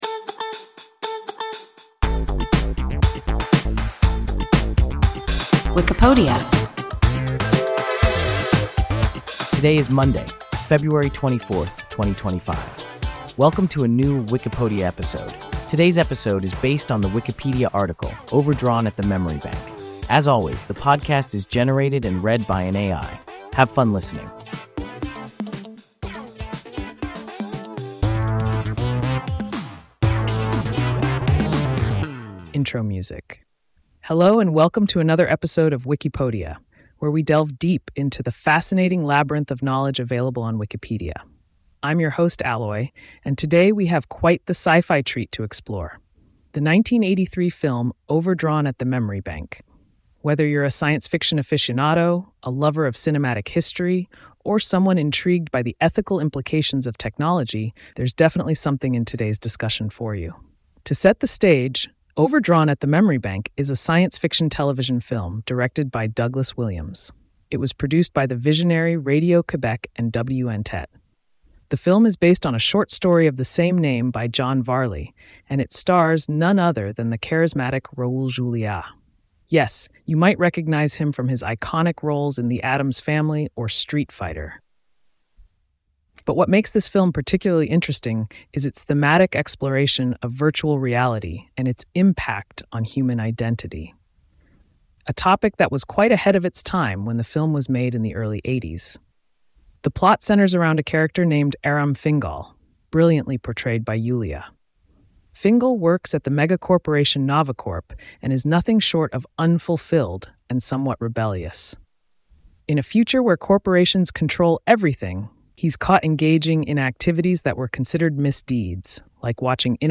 Overdrawn at the Memory Bank – WIKIPODIA – ein KI Podcast